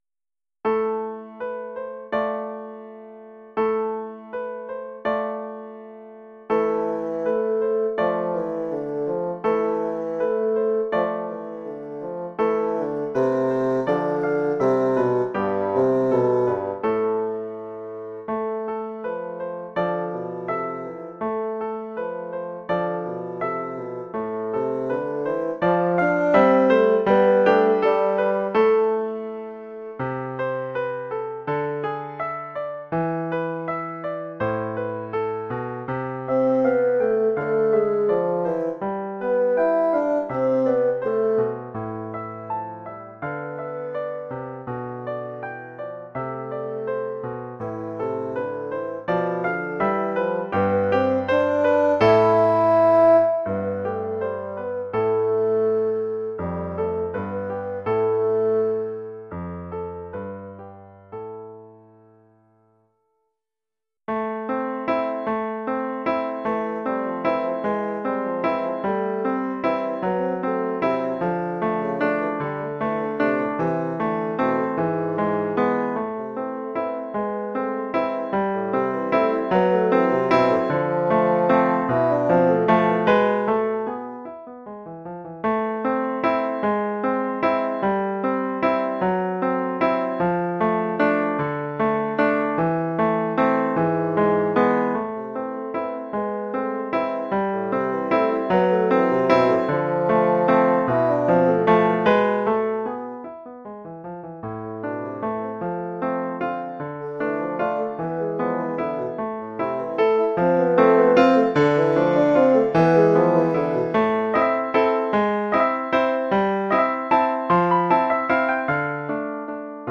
1 titre, basson et piano : conducteur et partie de basson
Sous-titre : "Introduction et valse".
Oeuvre pour basson et piano.